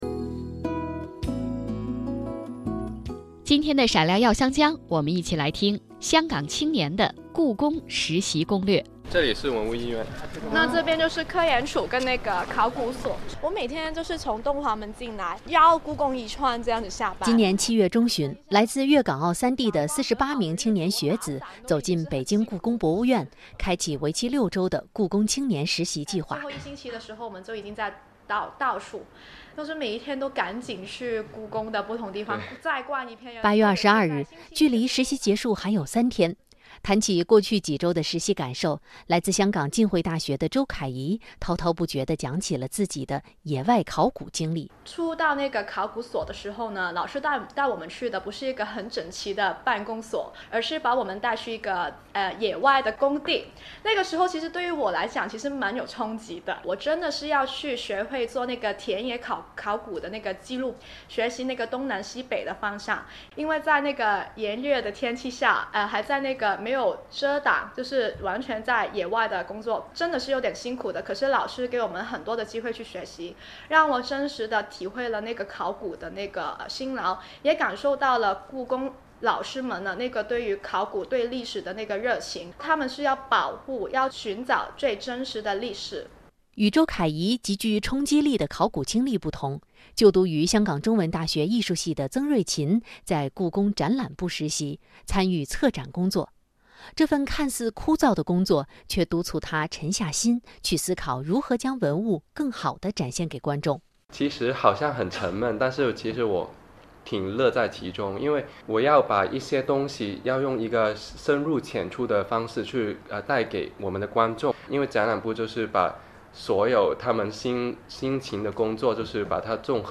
随着两地交流日益扩大，驻京办加强了在华北地区的宣传和推广工作，并自2006年起与中央人民广播电台「华夏之声」（2019年9月起更名为中央广播电视总台大湾区之声）携手打造普通话广播节目「每周听香港」，在华北九个省、市、自治区级电台播出，以趣味与信息并重的形式，把香港的最新发展带给当地听众。